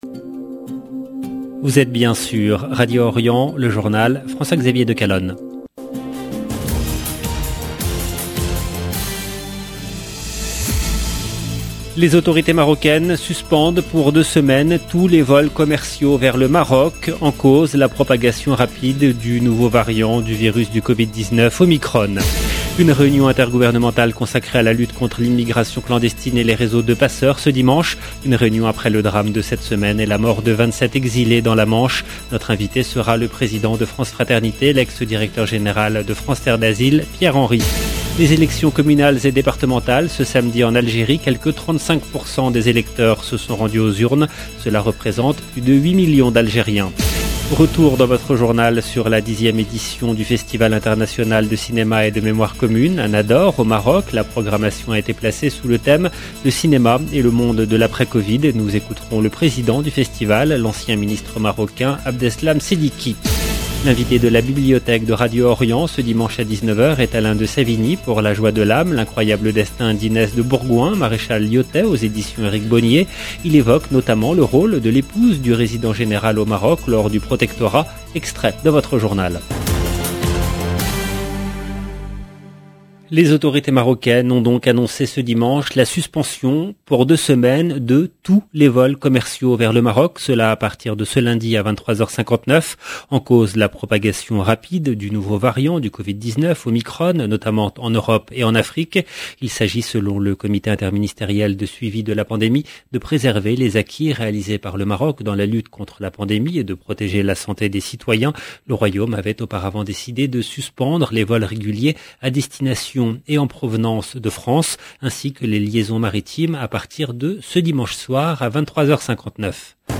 EDITION DU JOURNAL DU SOIR EN LANGUE FRANCAISE DU 28/11/2021
Nous écouterons le président du festival, l’ancien ministre Abdeslam Seddiki.